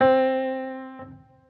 Piano - Lofi.wav